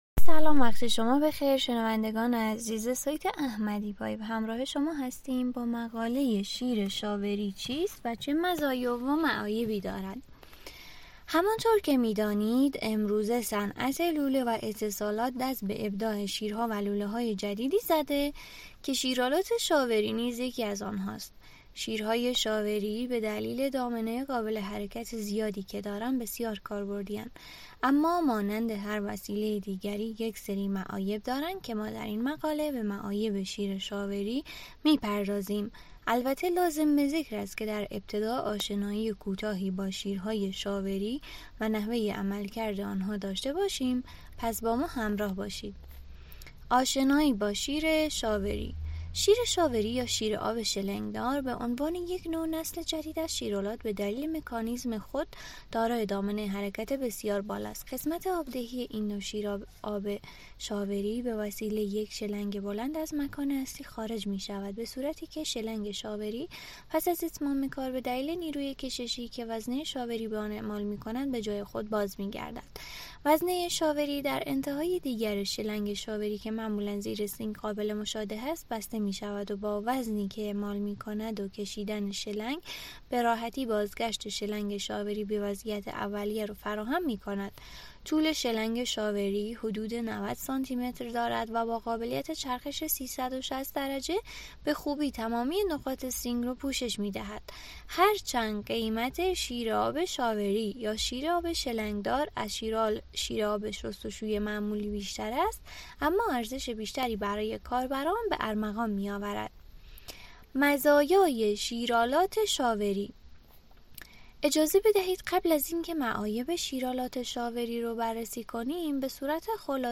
تمامی متن مقاله شیر شاوری چیست؟ چه مزایا و معایبی دارد؟ را می توانید به صورت pdf و در قالب یک پادکست گوش دهید.